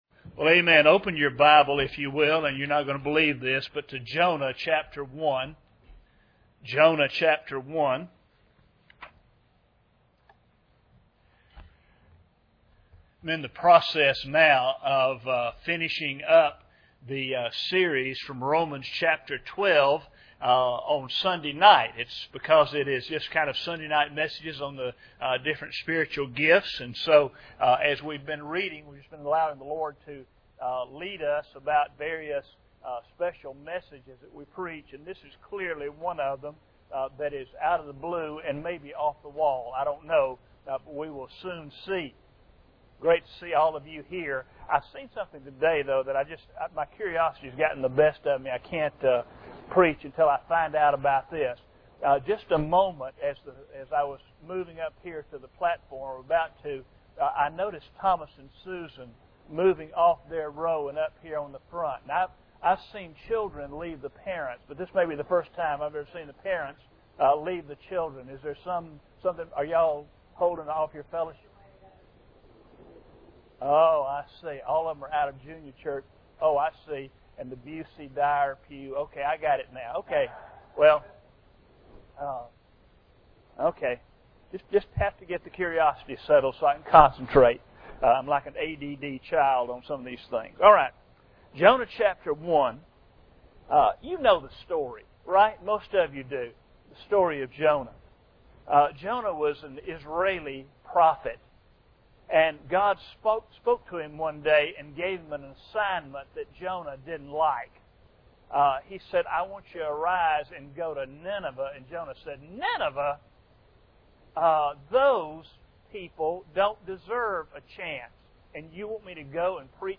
Jonah 1:11-12 Service Type: Sunday Morning Bible Text